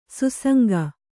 ♪ susanga